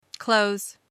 A pronúncia mais comum dessa palavra é a mesma pronúncia da palavra “close” (do verbo ‘fechar’). Portanto, a pronúncia será com som de /z/, ou seja, /klouz/.
/kloʊz/